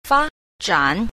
1. 發展 – fāzhǎn – phát triển